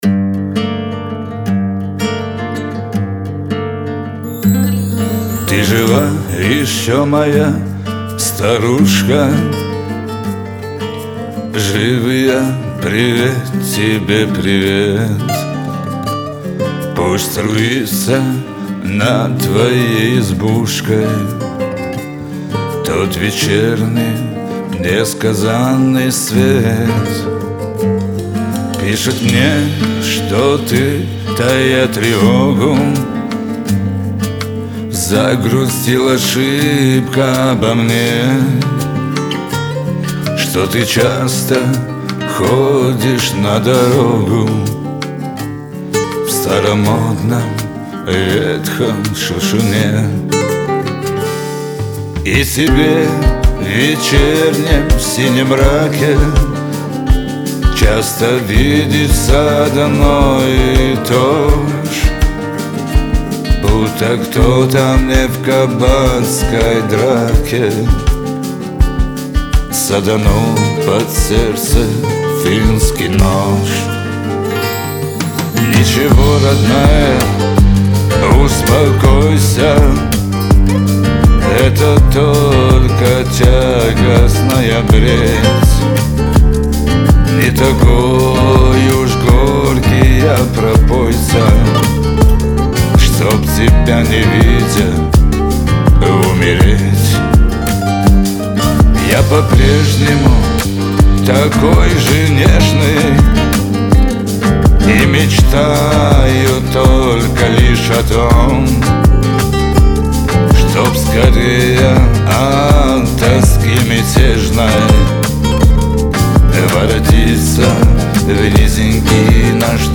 грусть , надежда , Кавказ – поп